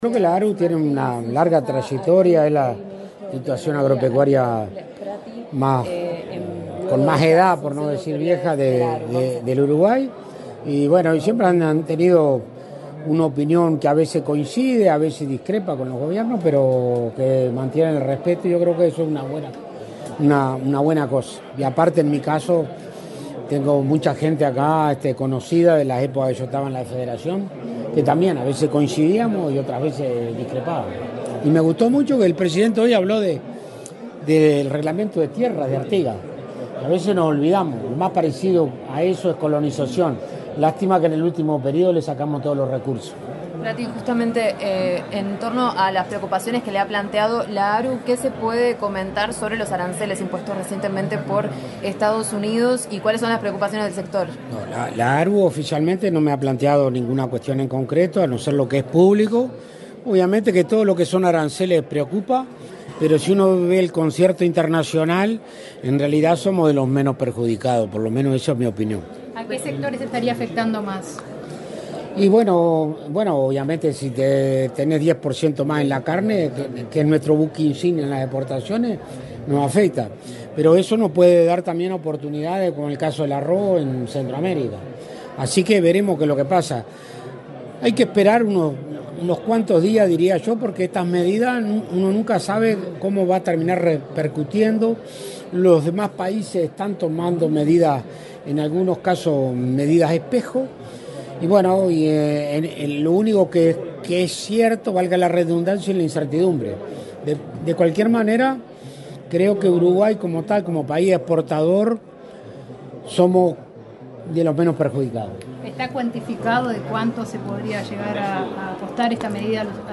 Declaraciones a la prensa del ministro de Ganadería, Alfredo Fratti
El ministro de Ganadería, Agricultura y Pesca, Alfredo Fratti, dialogó con la prensa, luego de participar de la ceremonia de asunción de las